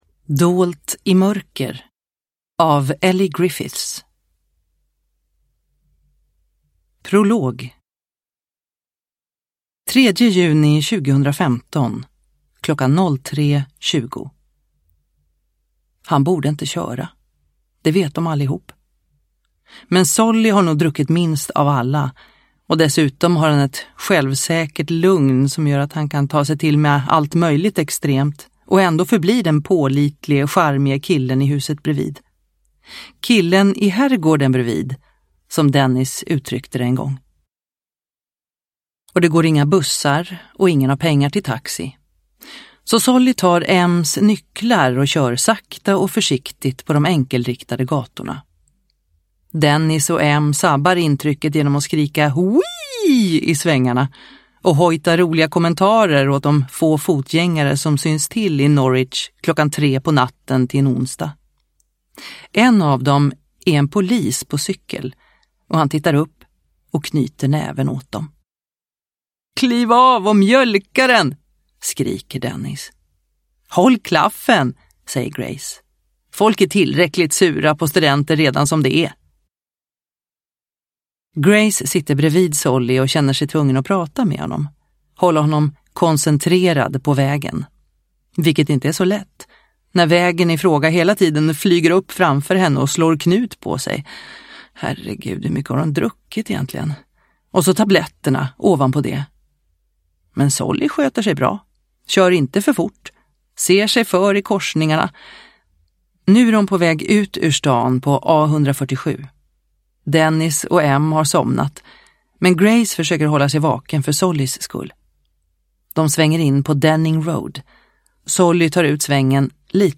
Dolt i mörker – Ljudbok – Laddas ner